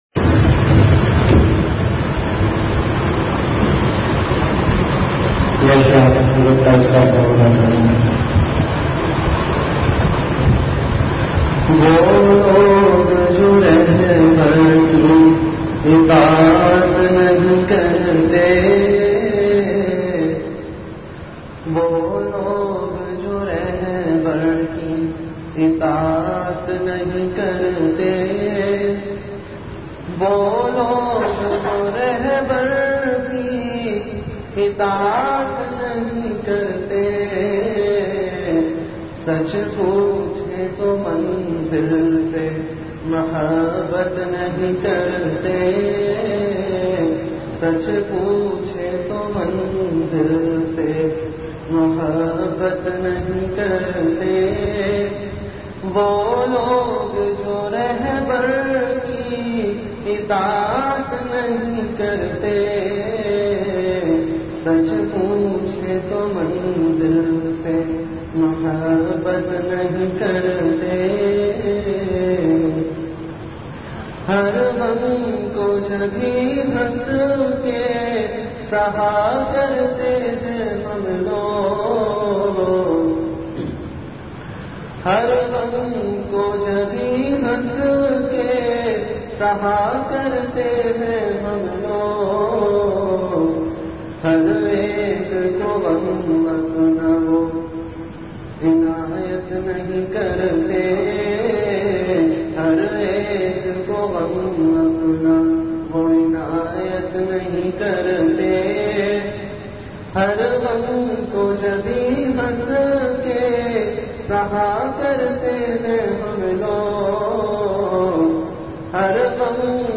Delivered at Home.
Majlis-e-Zikr
Event / Time After Isha Prayer